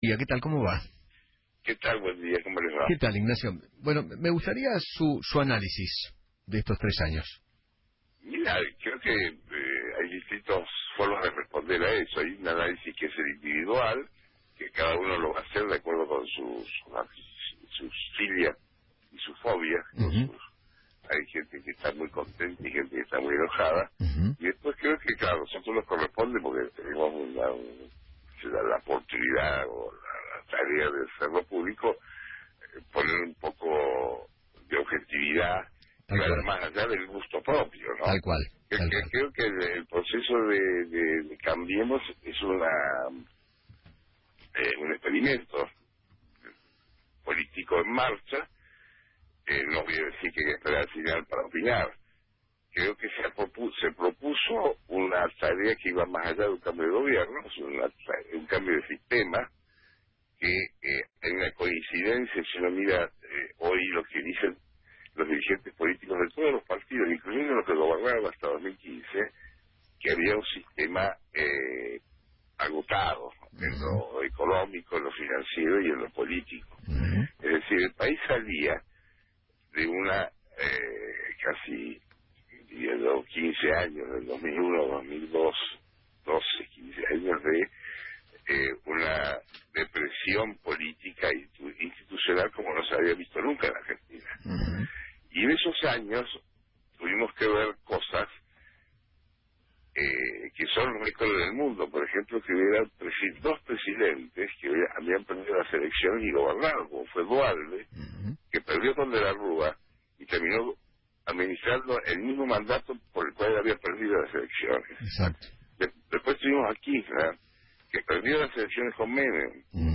Ignacio Zuleta, analista político, habló en Feinmann 910 y dijo que “De los tres años de Mauricio Macri se puede hacer el balance individual, hay gente enojada y después el análisis general. El proceso de Cambiemos es un experimento político en marcha”